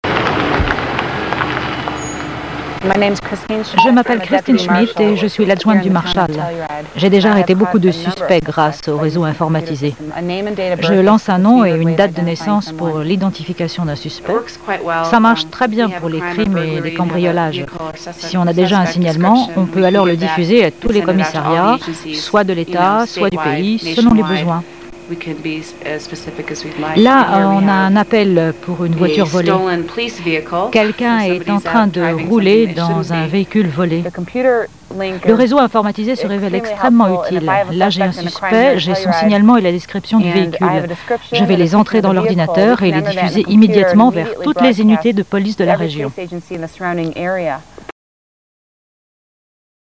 voice over pour Arte
Voix off